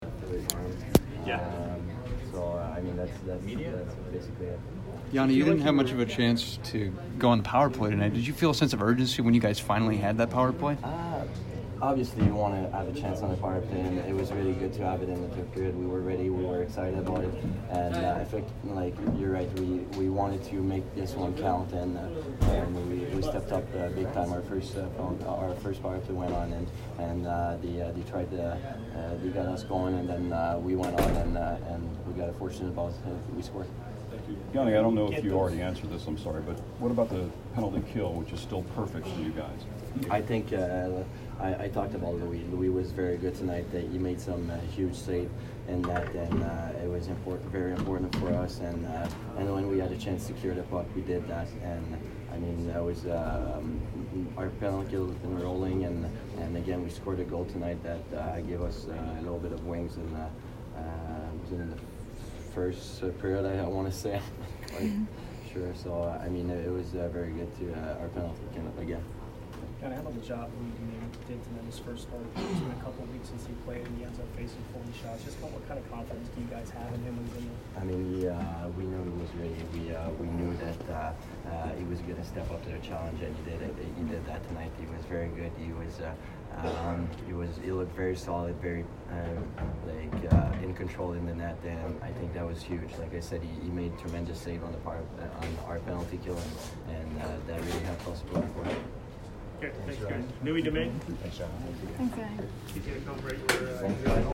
Yanni Gourde post-game 10/16